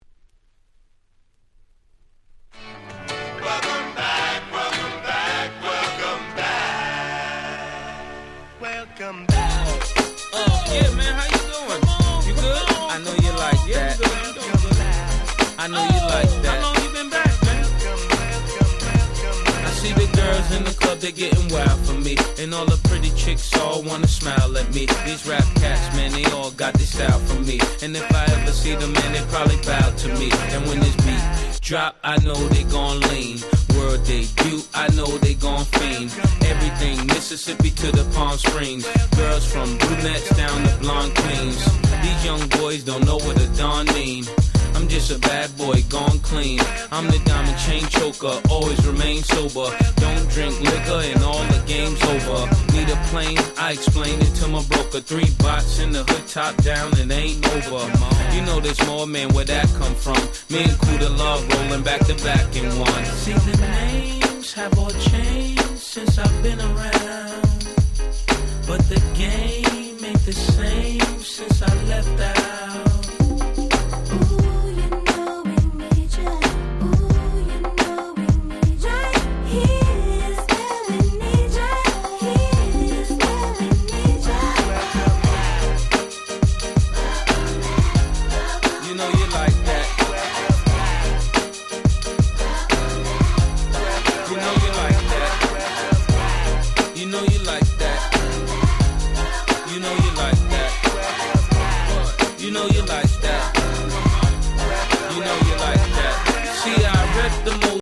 04' Smash Hit Hip Hop !!